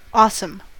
awesome: Wikimedia Commons US English Pronunciations
En-us-awesome.WAV